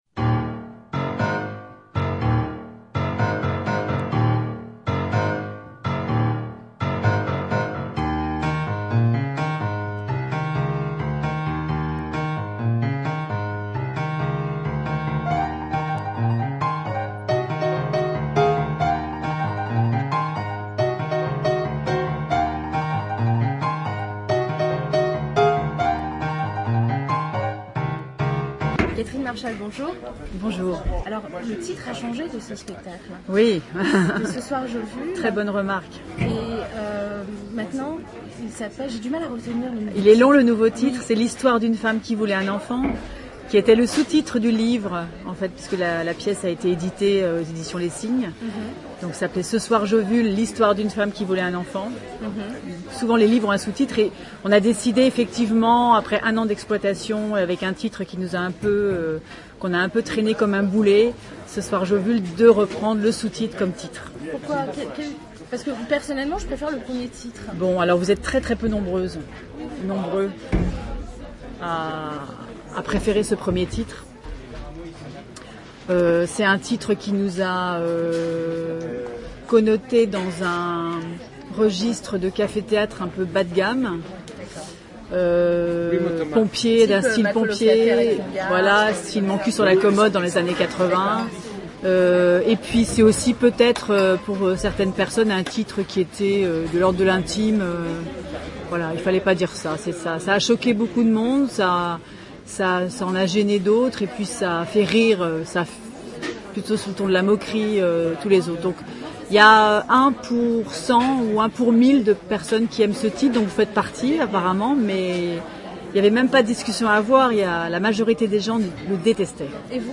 Dans cette interview, Catherine Marchal raconte (entre autre) son coup de foudre avec cette pièce… étonnante.